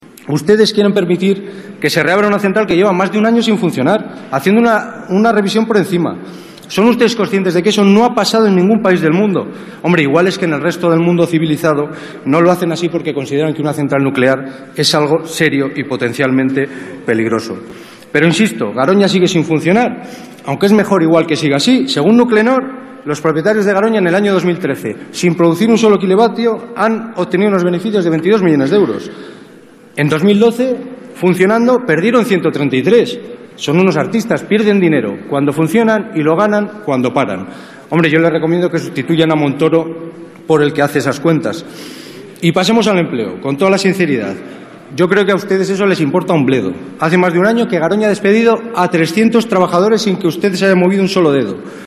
Luid Tudanca en el pleno del Congreso pide el cierre de Garoña 18/03/2014